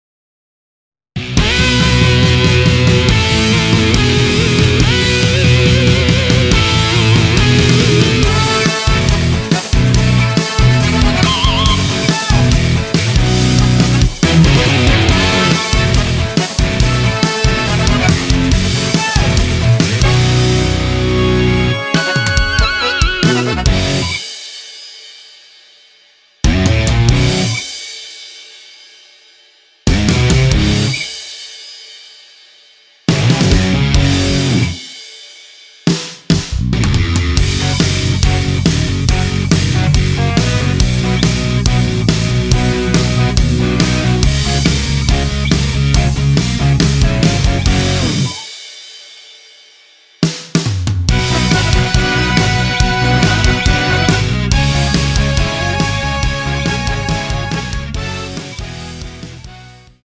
원키에서(-2)내린 MR입니다.
F#
앞부분30초, 뒷부분30초씩 편집해서 올려 드리고 있습니다.
중간에 음이 끈어지고 다시 나오는 이유는